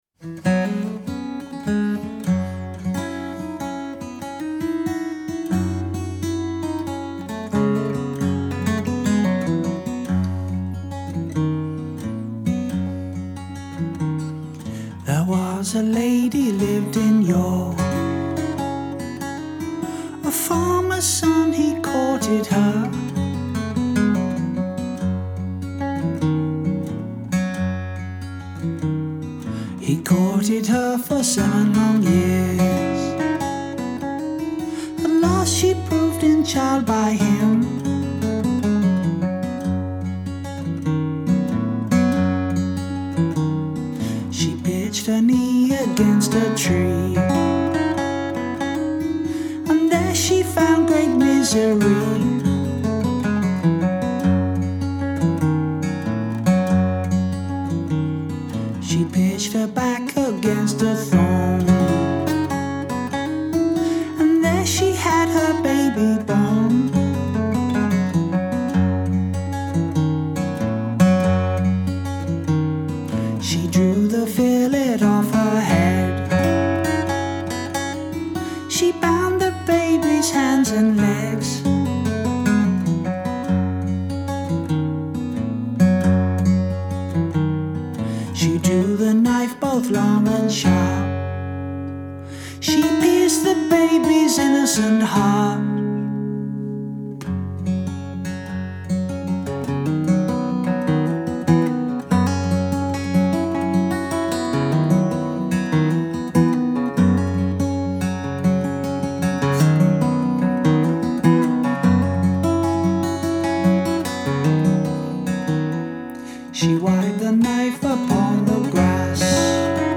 Working with traditional folk music is a journey into cultural history, it’s an interaction with the past & how we relate to it.
It is a beautiful ugly desperate song. It was sung widely across Scotland & England in the 19th century
It is a very sad song.